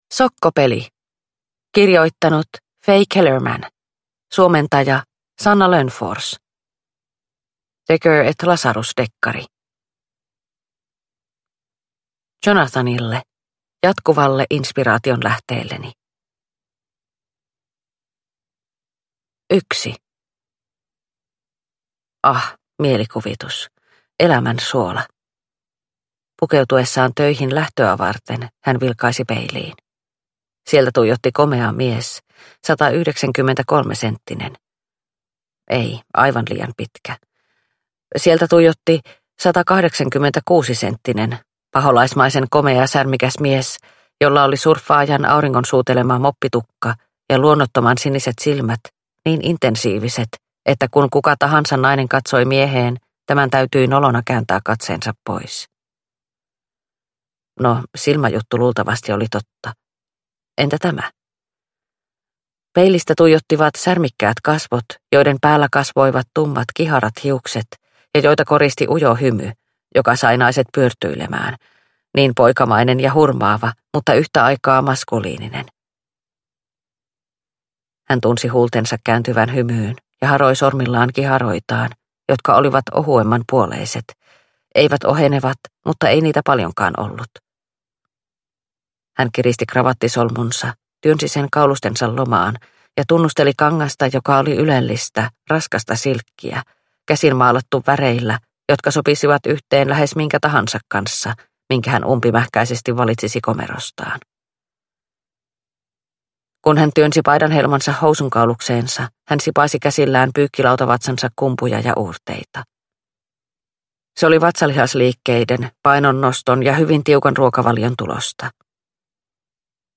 Sokkopeli – Ljudbok – Laddas ner